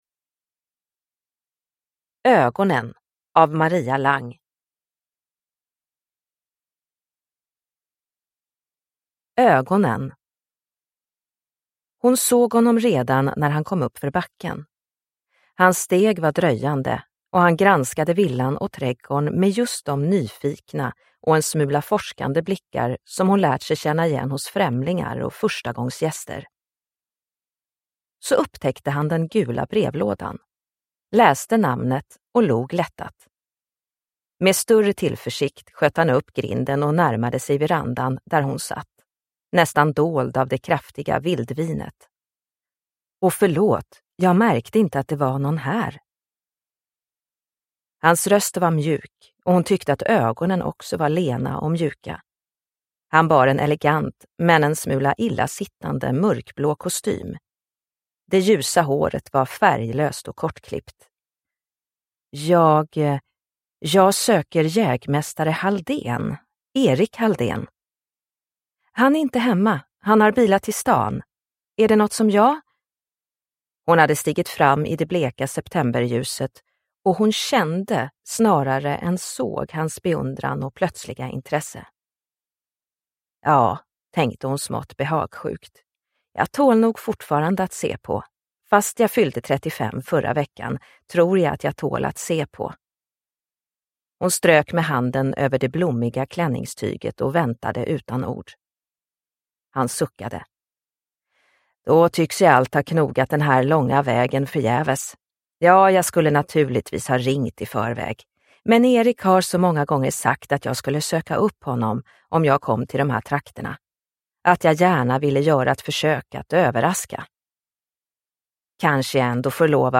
Ögonen – Ljudbok – Laddas ner